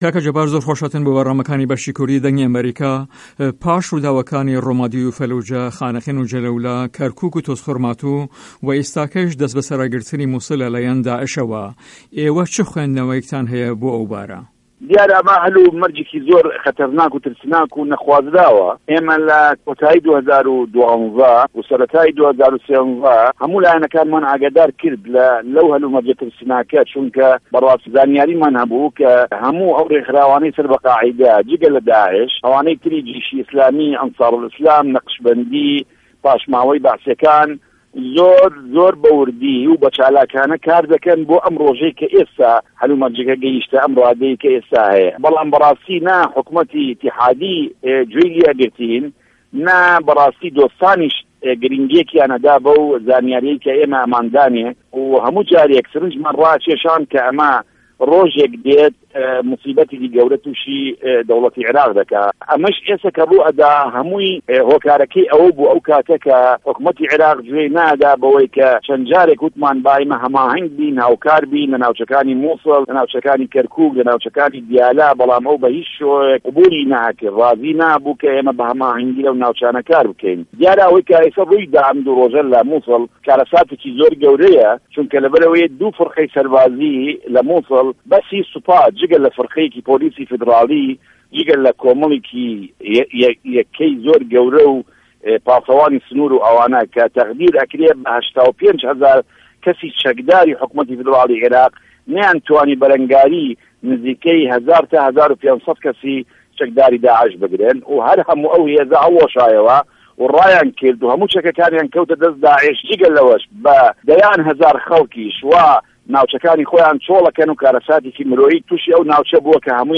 جه‌بار یاوه‌ر ووته‌ بێژی هێزه‌کانی پاراستی هه‌رێمی کوردستان و سکرتێری گشتی وه‌زاره‌تی پێشمه‌رگه‌ له‌ هه‌ڤپه‌ێڤینێکدا له‌گه‌ڵ به‌شی کوردی ده‌نگی ئه‌مه‌ریکا ده‌ڵێت"دیاره‌ ئه‌مه‌ هه‌ل و مه‌رجێکی زۆر ترسناک و نه‌خوازراوه‌، ئێمه‌ له‌ کۆتایی 2012 و ‌سه‌ره‌تا 2013 هه‌موو لایه‌نه‌کانمان ئاگادار کرد له‌و هه‌ل و مه‌رجه‌ ترسناکه‌، چۆنکه‌ زانیاریمان هه‌بوو که‌ هه‌موو ئه‌و رێکخراوانه‌ی سه‌ر به‌ القاعده‌ن جگه‌ له‌ داعش، ئه‌وانه‌ی تری جیشی ئیسلامی، ئه‌نسار و ئیسلام، نه‌قشبه‌ندی، پاشماوه‌ی به‌عسیه‌کان، زۆر زۆر به‌ ووردی و به‌ چالاکانه‌ کار ده‌که‌ن بۆ ئه‌م رۆژه‌ی که‌ ئێستا هه‌ل و مه‌رجه‌که‌ گه‌ێشته‌ ئه‌م راده‌یه‌ی که‌ ئێستا هه‌یه‌.